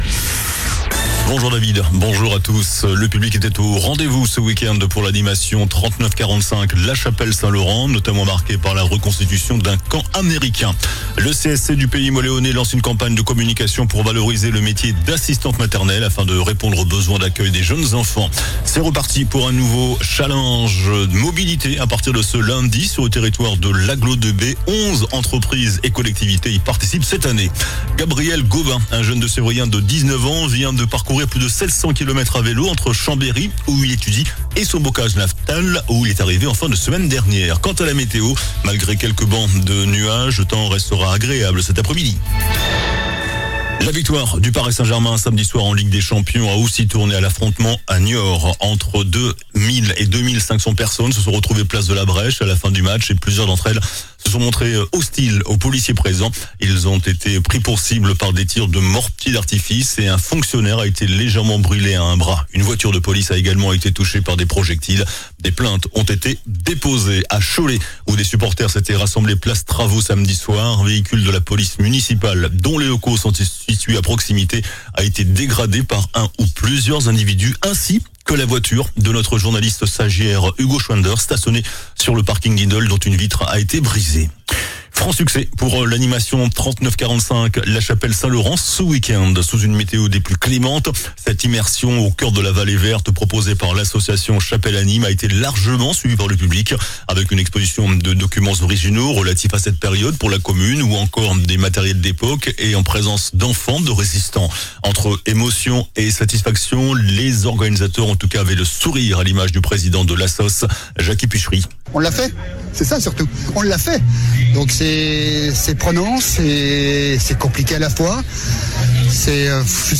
JOURNAL DU LUNDI 02 JUIN ( MIDI )